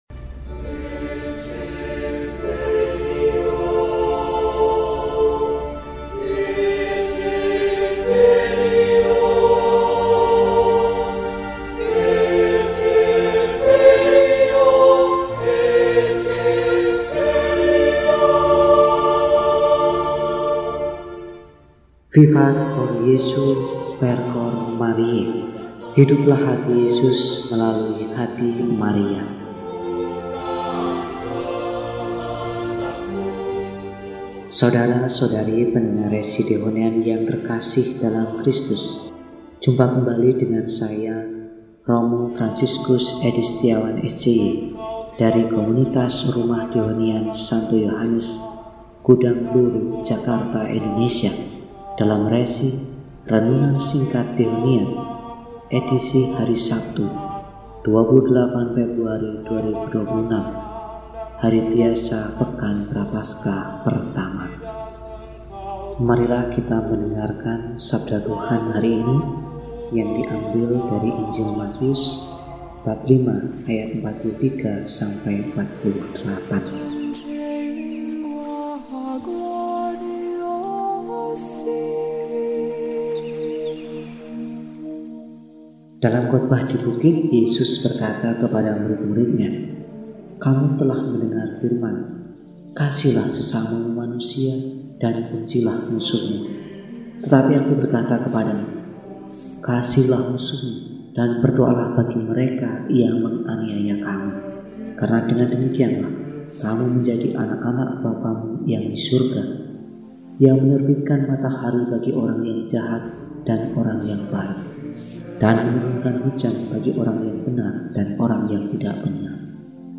Sabtu, 28 Februari 2026 – Hari Biasa Pekan I Prapaskah – RESI (Renungan Singkat) DEHONIAN